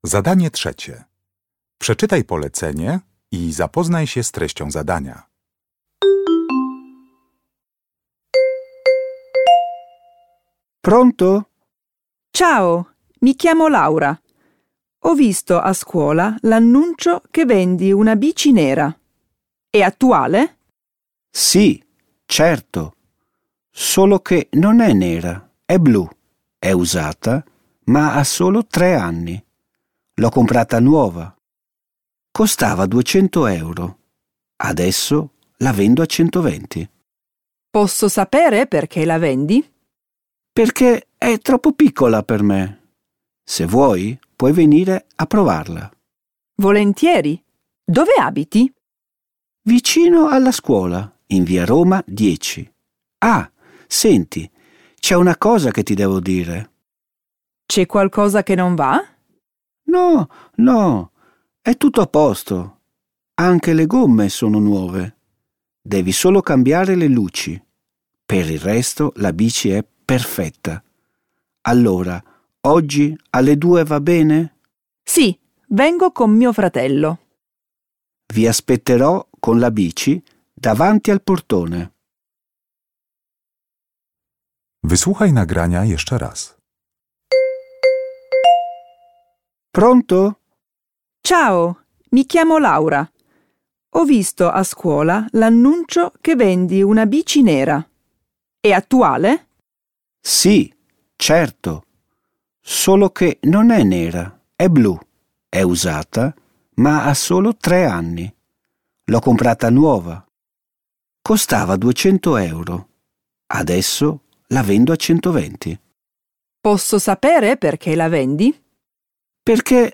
Uruchamiając odtwarzacz z oryginalnym nagraniem CKE usłyszysz dwukrotnie rozmowę na temat sprzedaży roweru.